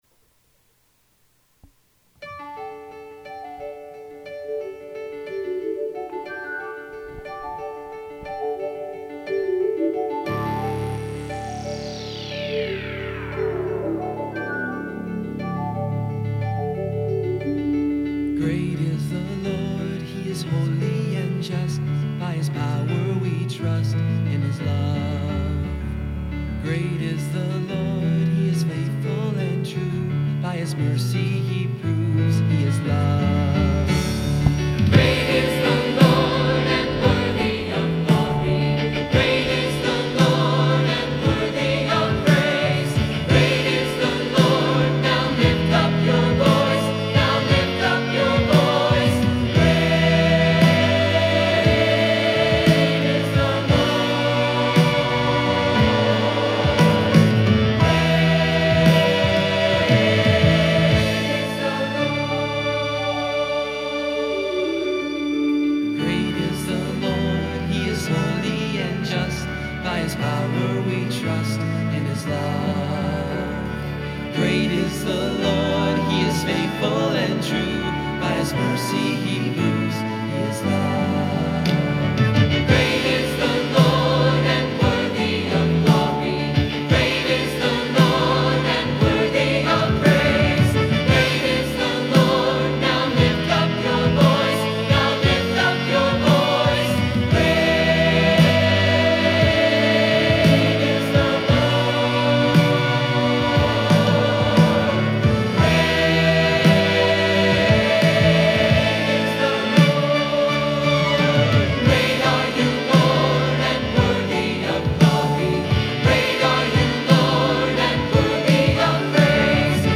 CLICK HERE FOR SONGS, SCRIPTURE, AND SERMON (approximately 45 minutes)